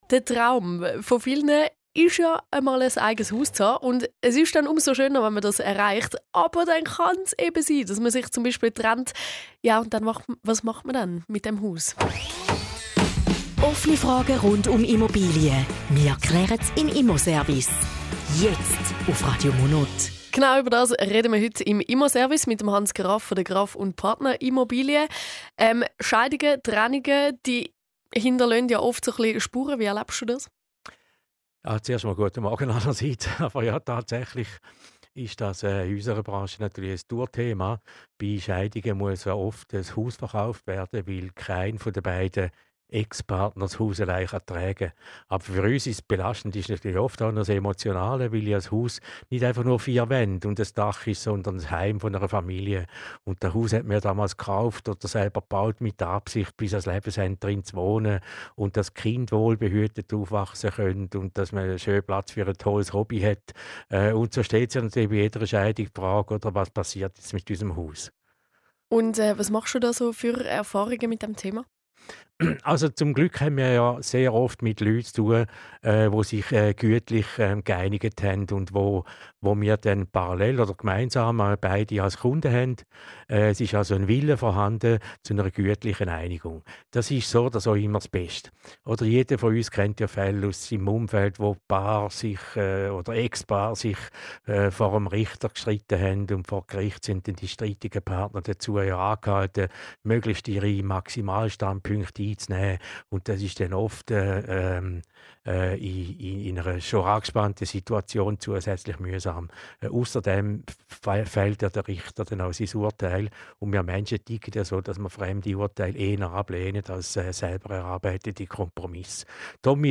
Zusammenfassung des Interviews zum Thema "Scheidung und Trennung - was passiert mit dem Haus":